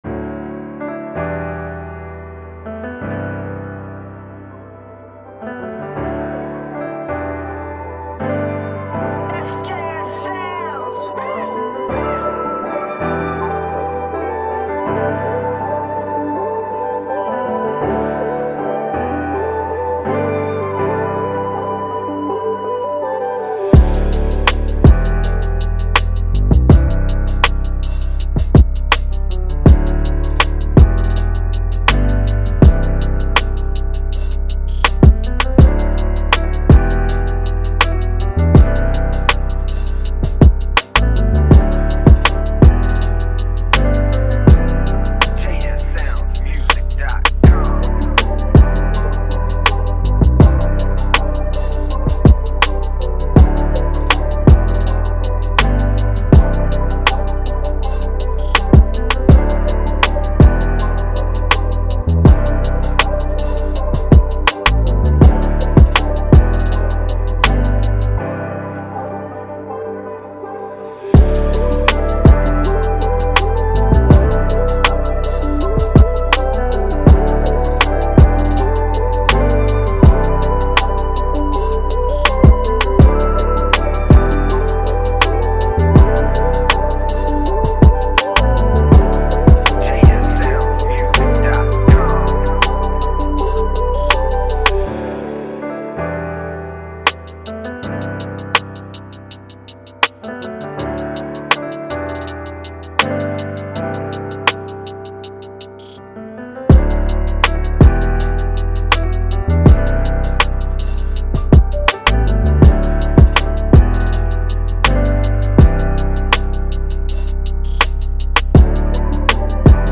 Melodic Type Beat